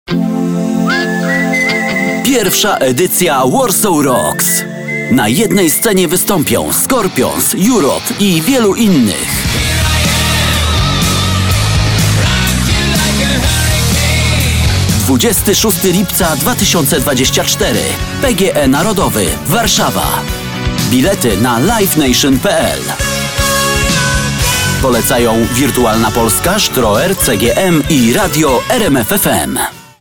Male 30-50 lat
Zapowiedź koncertu